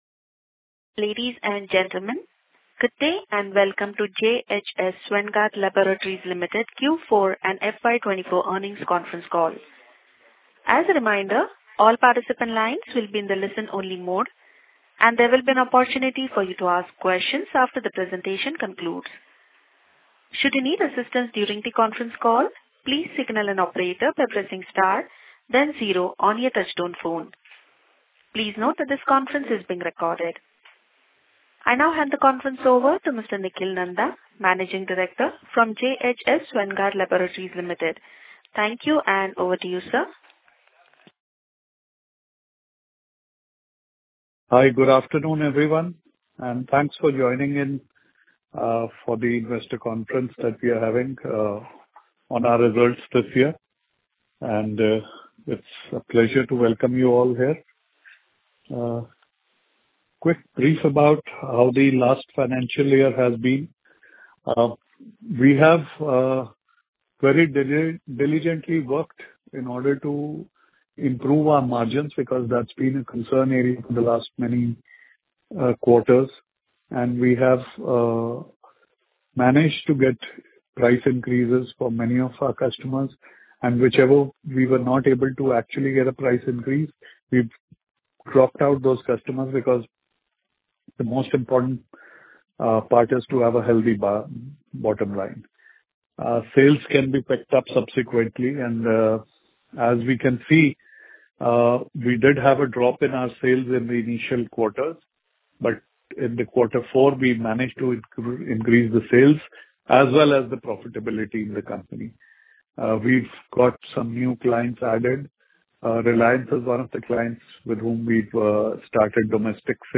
Conference Call